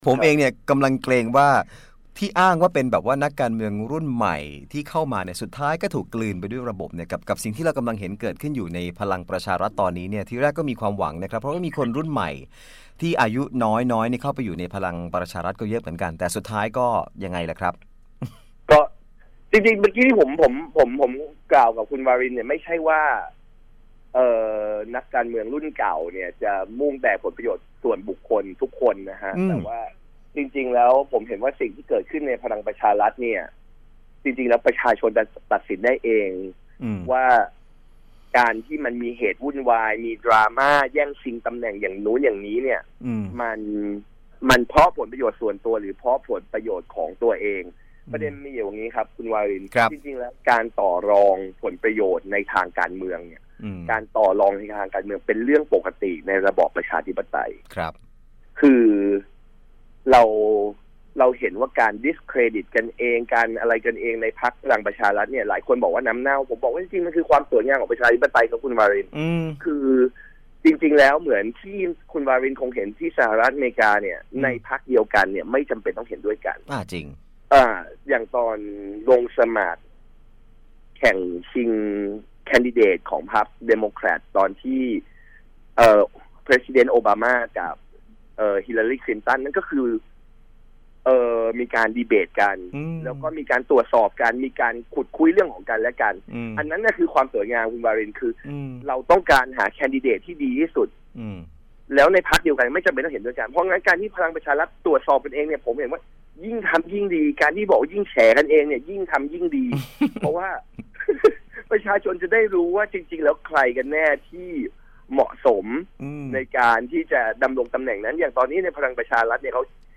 สัมภาษณ์